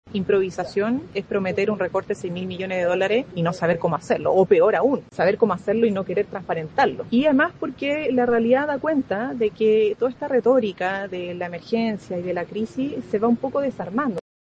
En tanto, la vocera de Gobierno, Camila Vallejo, dijo que improvisar es promover un recorte de 6 mil millones de dólares y no transparentar el cómo hacerlo.